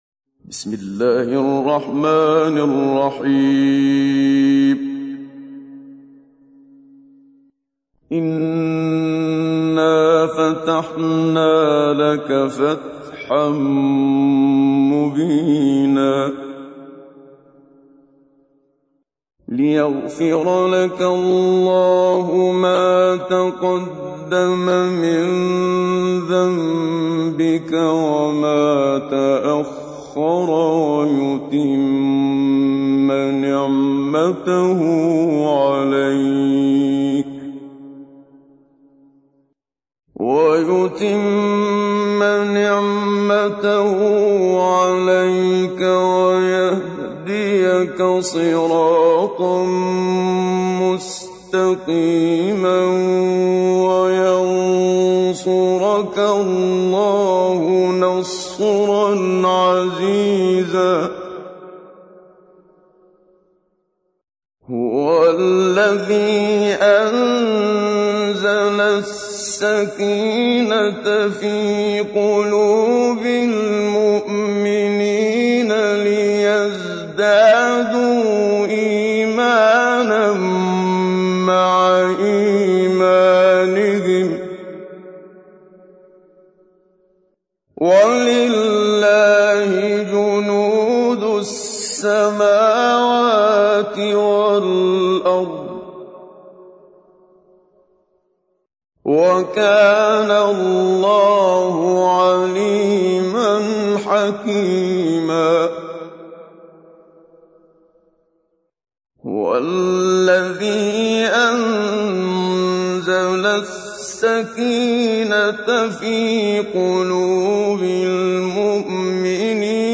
تحميل : 48. سورة الفتح / القارئ محمد صديق المنشاوي / القرآن الكريم / موقع يا حسين